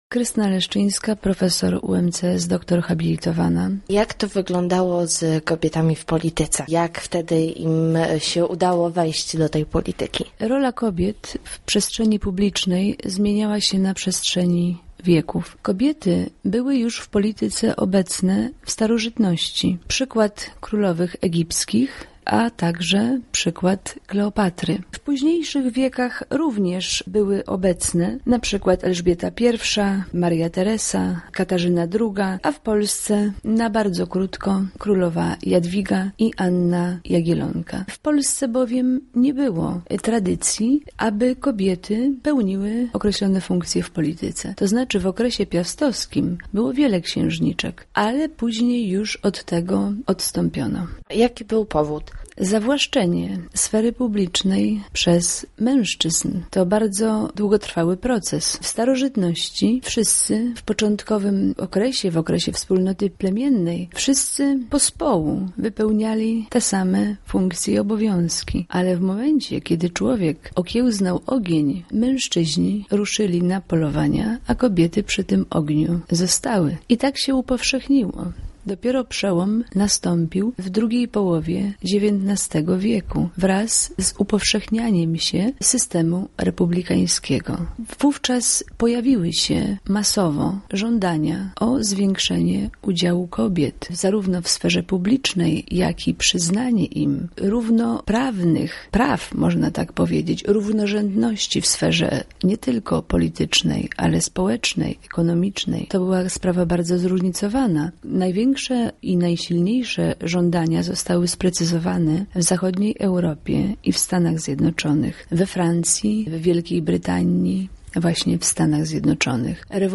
Ta nietypowa rocznica zmotywowała nas do tego żeby porozmawiać z ekspertem na temat kobiet w polityce.